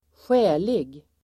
Uttal: [²sj'ä:lig]